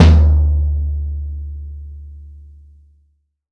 Index of /90_sSampleCDs/AKAI S6000 CD-ROM - Volume 3/Kick/GONG_BASS